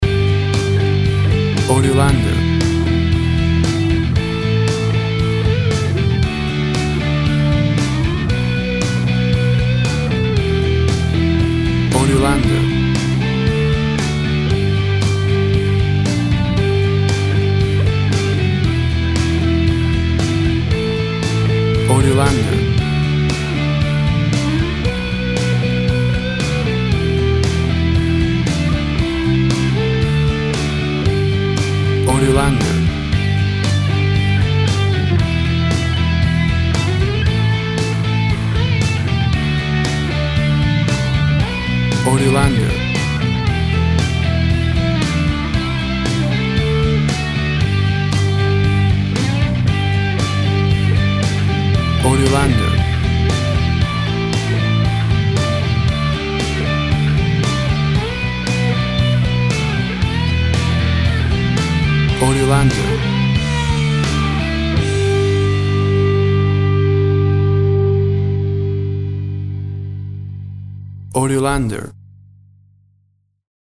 A big and powerful rocking version
Full of happy joyful festive sounds and holiday feeling!
Tempo (BPM) 100